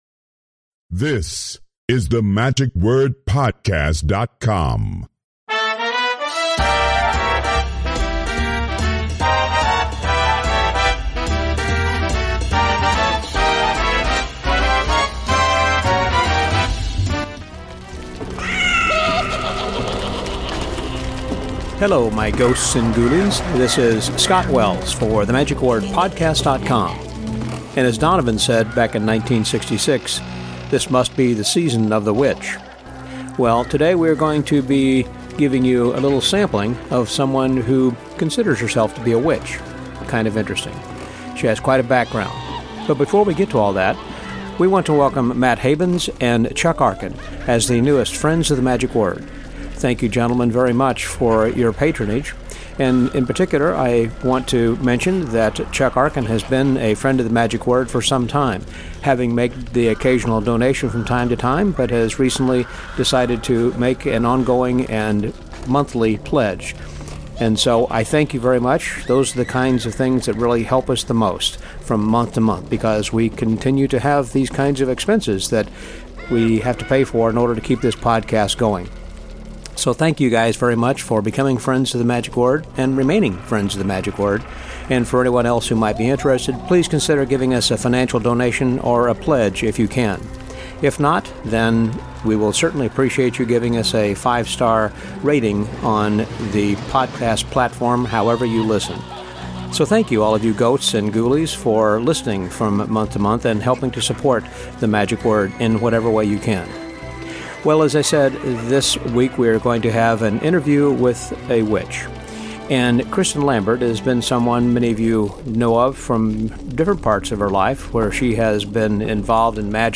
Interview with a Witch — The Magic Word